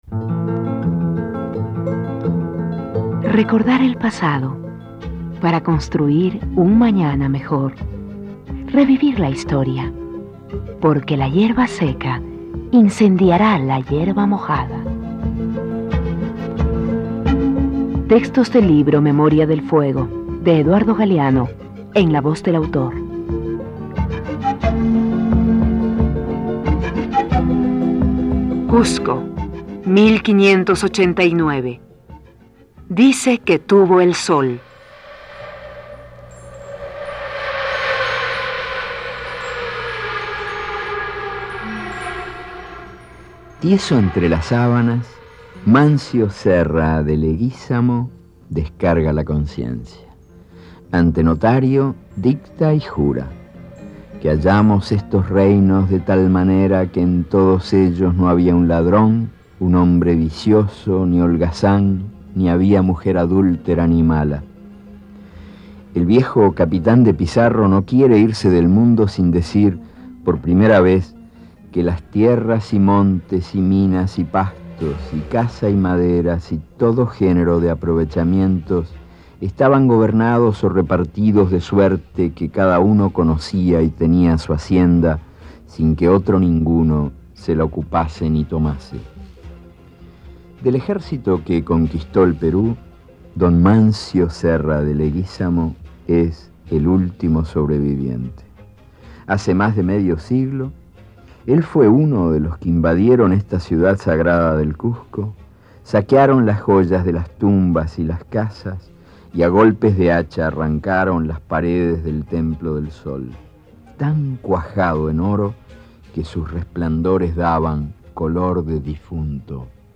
Este archivo de sonido ofrece la lectura del texto en la voz de su autor.
Eduardo Galeano_Dice que tuvo el sol.mp3